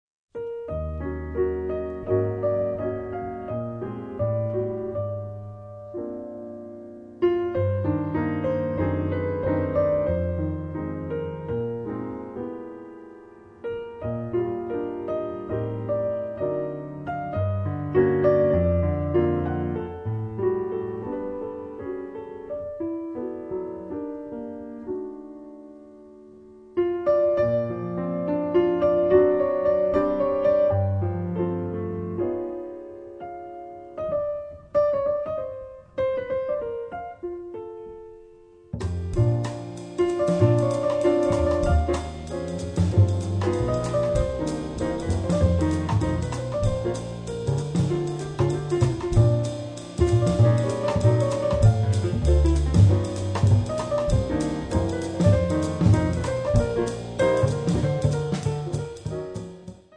piano
diventa un valzer